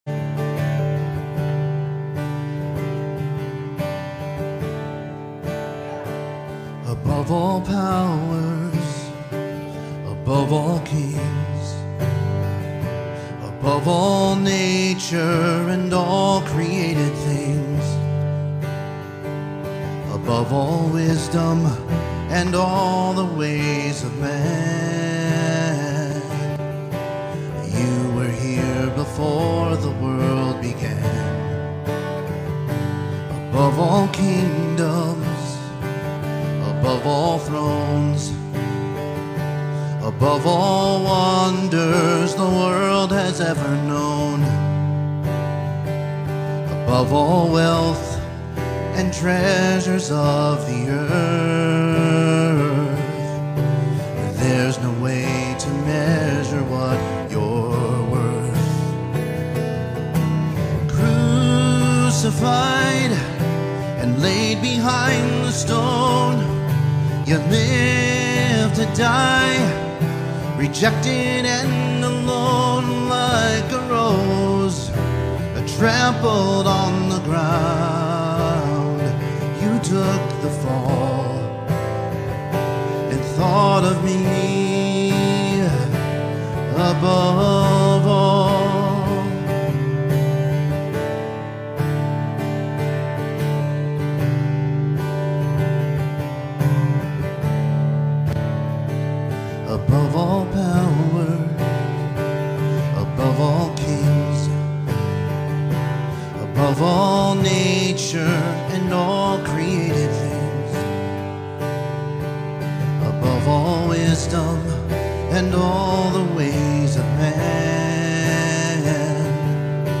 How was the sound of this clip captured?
Passage: Genesis 3 Service Type: Sunday Morning